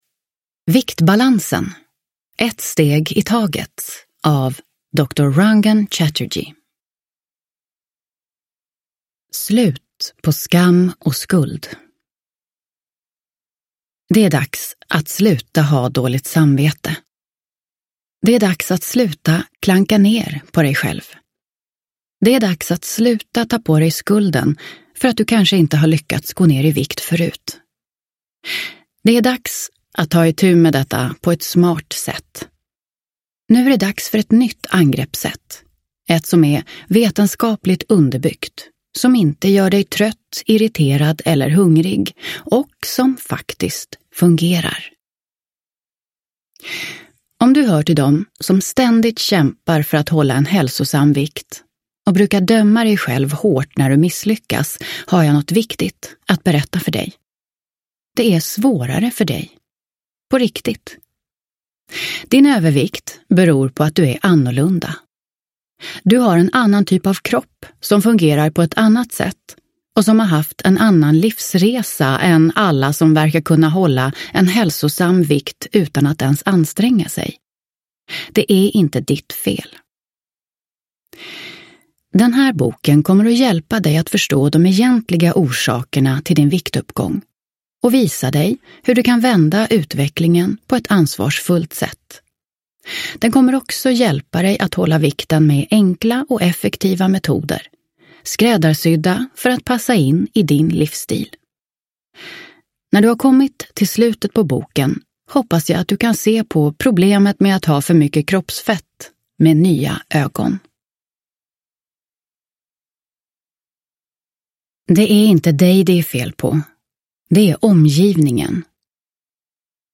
Viktbalansen : ett steg i taget – Ljudbok – Laddas ner